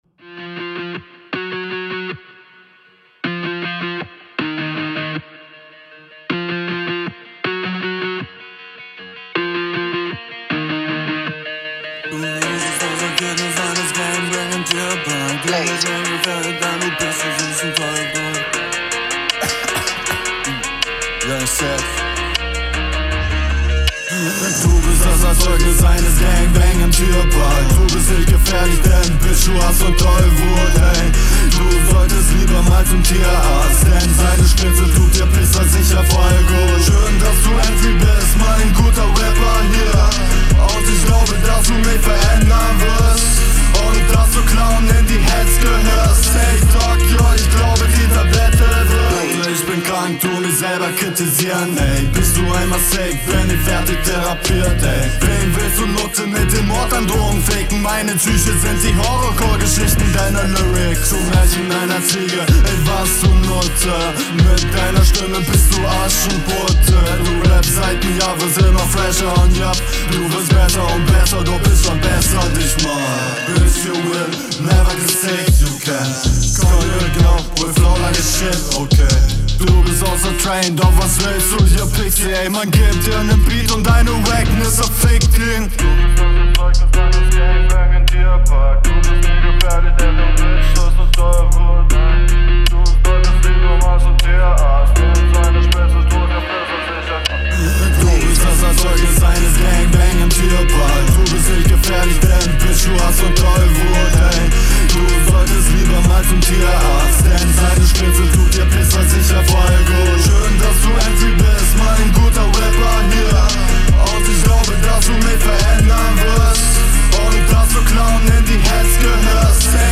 Flow: stabil aufm beat, sicherer Stimmeinsatz und musikalisch aber leider unsaubere doubles (ankliena) Text: nicht …
Flow: ist etwas schleppend, aber sonst ganz gut. Text: ein paar nette Punches, aber sonst …
Der Stimmeinsatz ist echt überheblich, die Delivery dadurch auch relativ gut. Der Flow ist durchgehend …